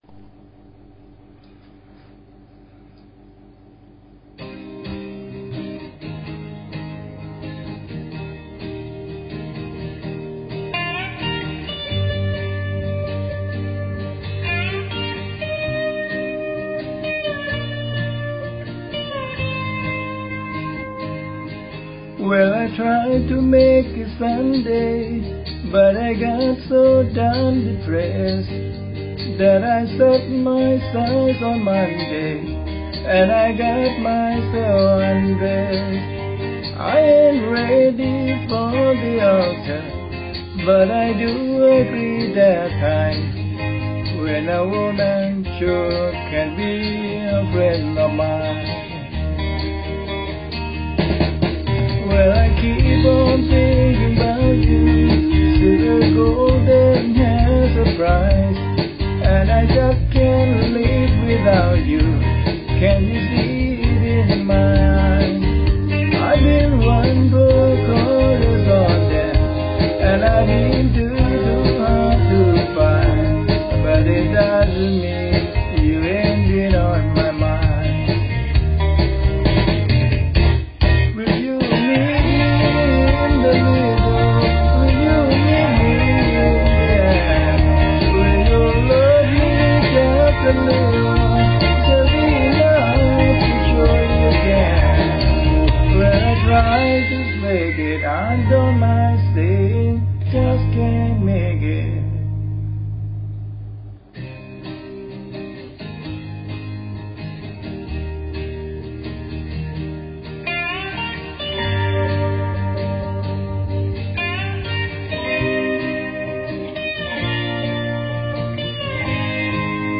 *** This is just our COVER of the song ...